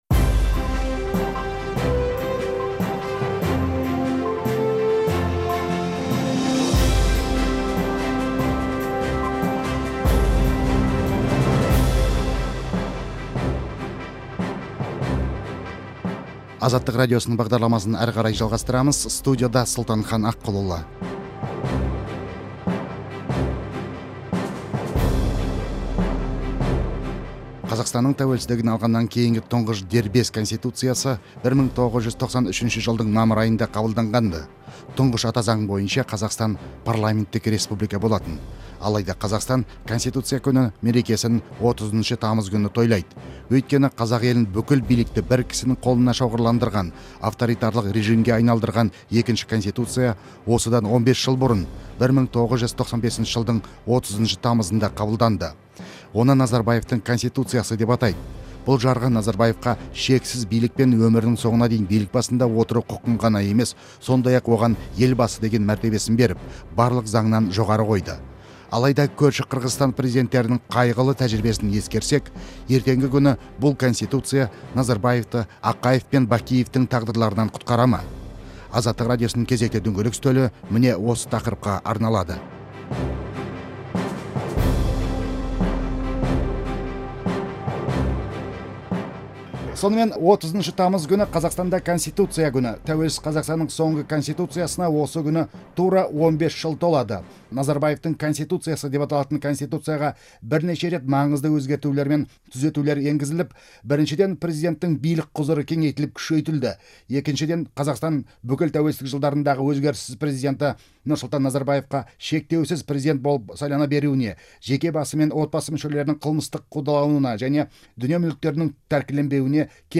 Конституция күніне арналған дөңгелек үстел талқылауы